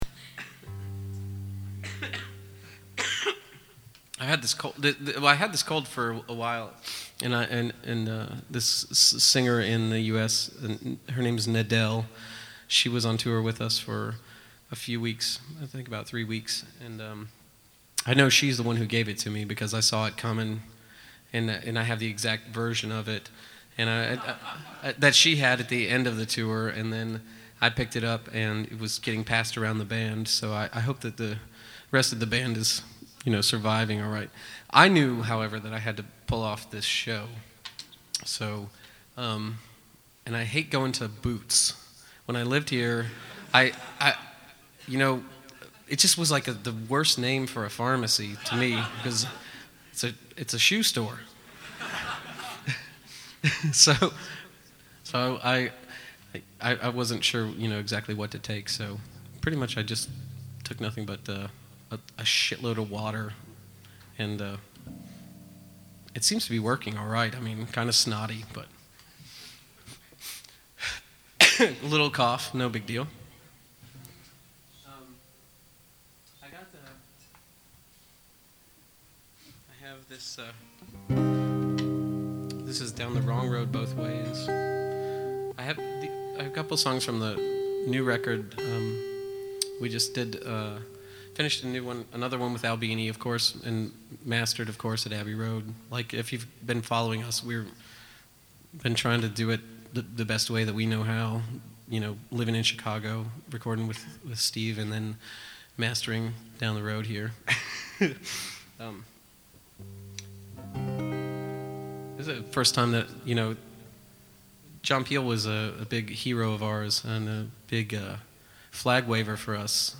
live at the Luminaire, London, UK, Apr 5, 2006.